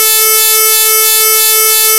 描述：我写的是基于FM的软件声音生成器生成的。
标签： 回路 电子 合成器 16位 高保真 样品 FM 单声道 双第二
声道立体声